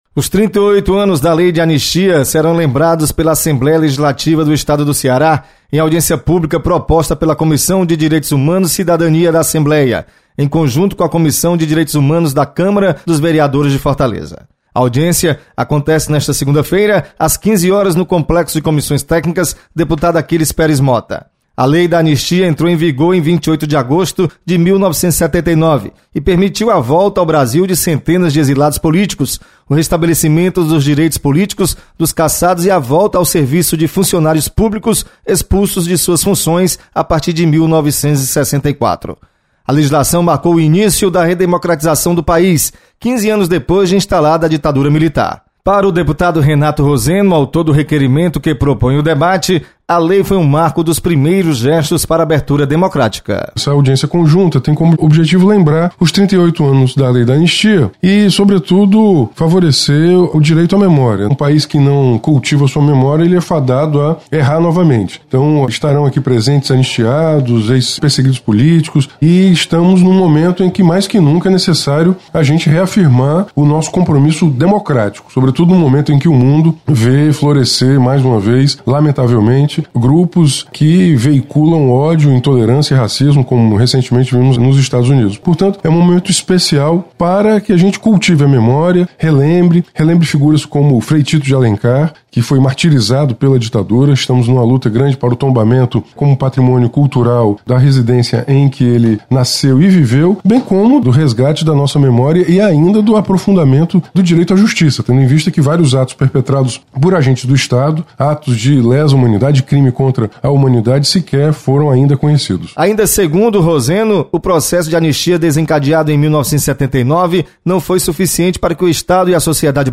Você está aqui: Início Comunicação Rádio FM Assembleia Notícias Audiência